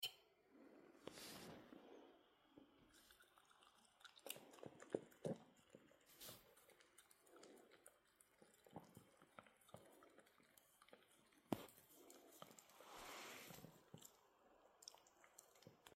Dog ASMR.